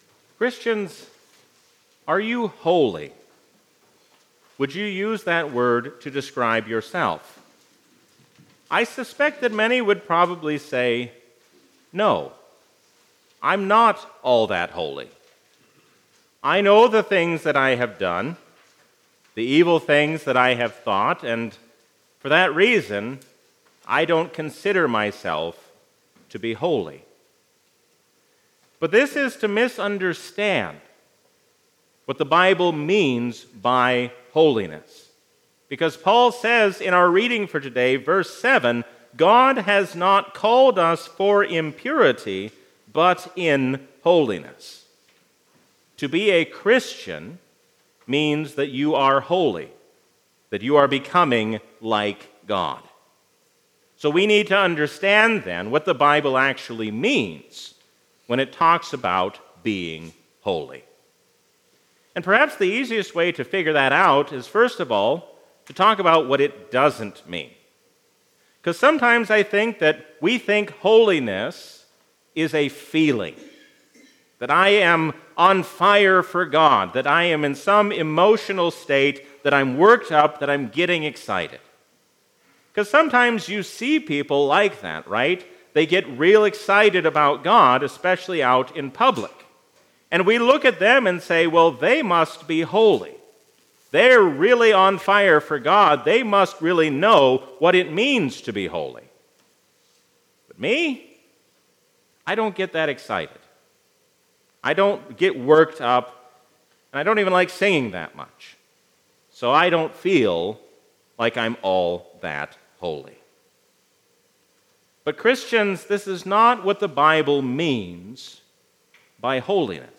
A sermon from the season "Lent 2022." Let us love, think, and act as one, because we are one in Christ.